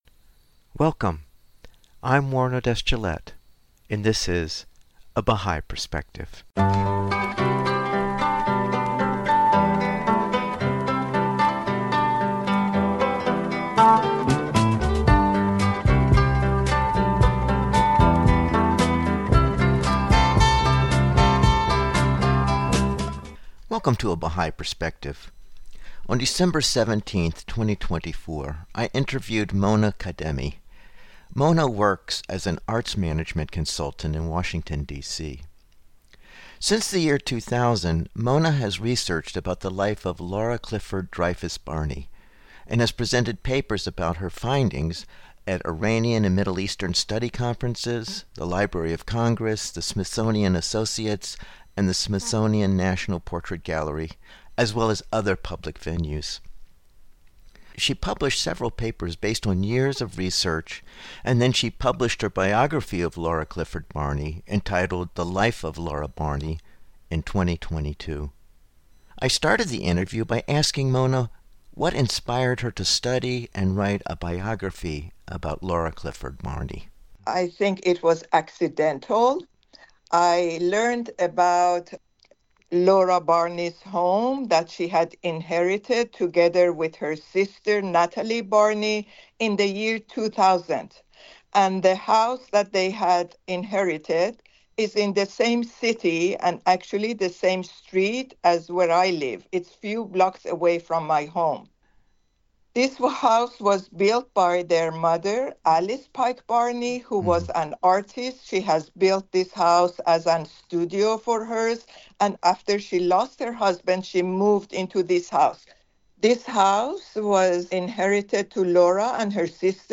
A weekly radio broadcast of biographical interviews of people who have either chosen the BAHÁÍI FAITH as a way of life or who have a relationship with the BAHÁÍI FAITH.